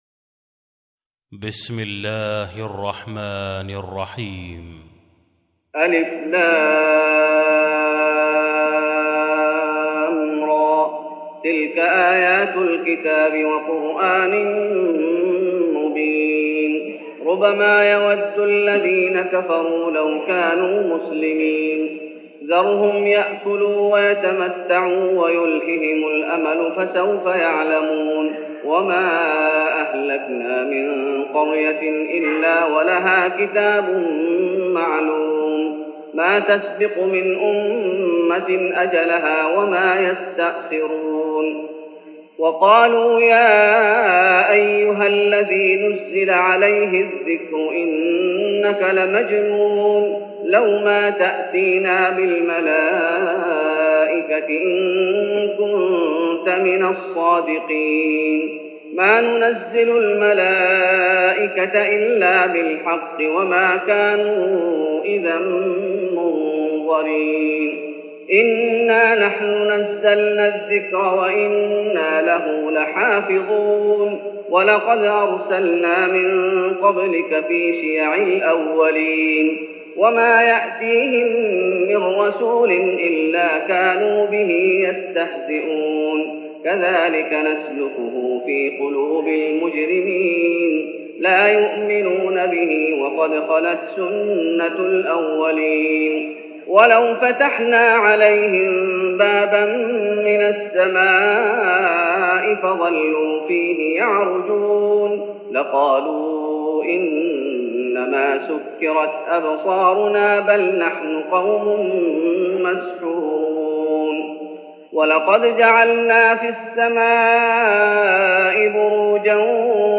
تهجد رمضان 1413هـ سورة الحجر | Tahajjud Ramadan 1413H from Surah Al-Hijr > تراويح الشيخ محمد أيوب بالنبوي 1413 🕌 > التراويح - تلاوات الحرمين